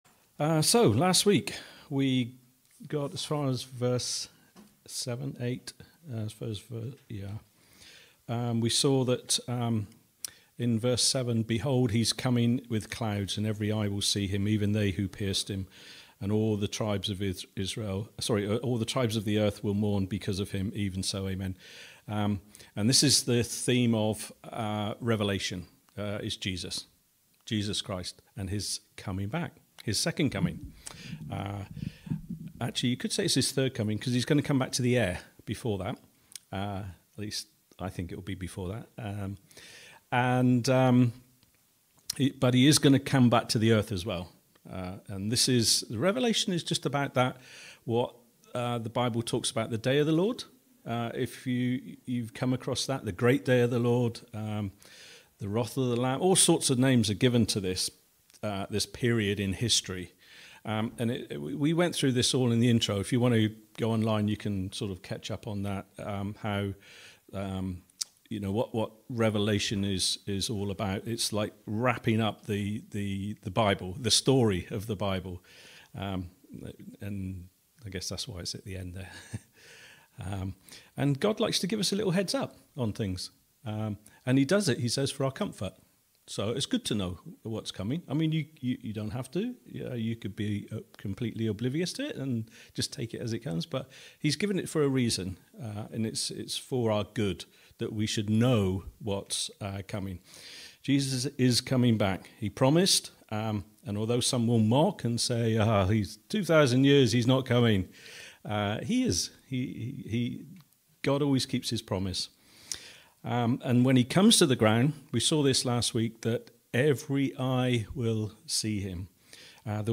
A message from the series "Revelation."
We're not sure why but the transmission seemed to cut out for a short while about 10 minutes before the end.